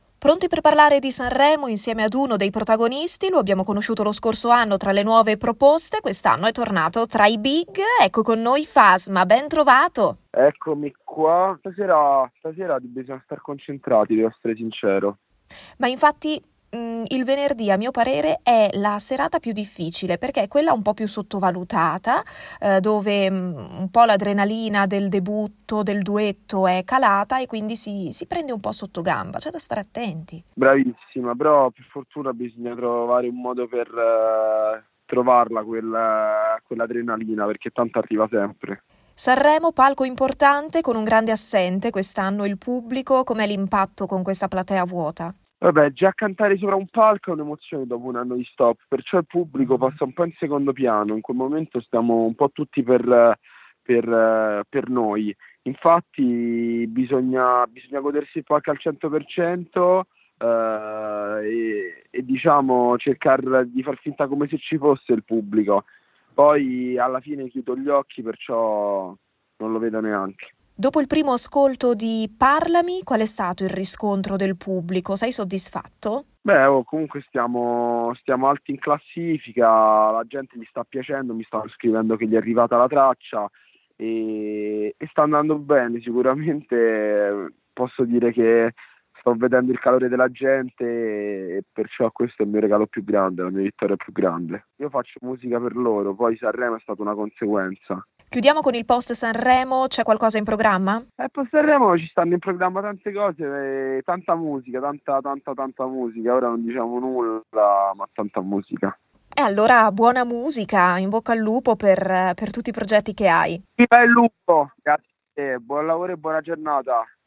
Sanremo 2021: Radio Pico intervista Fasma - Radio Pico